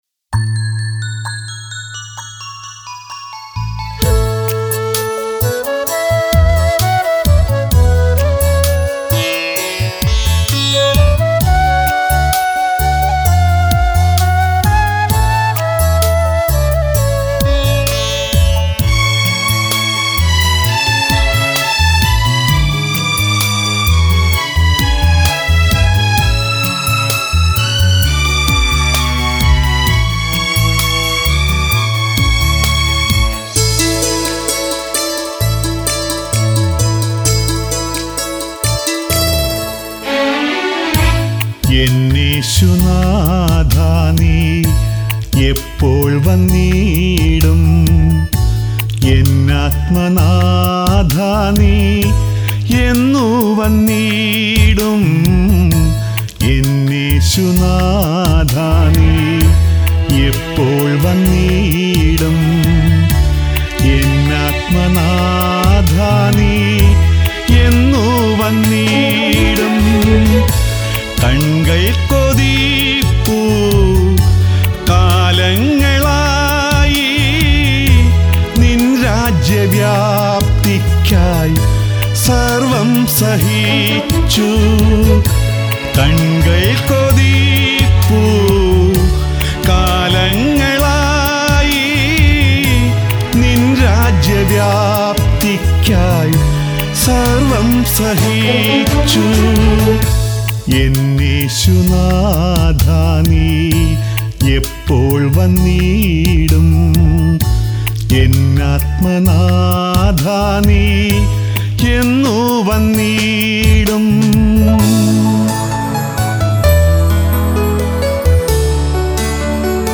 Keyboard Sequence
Flute
Tabala & Percussions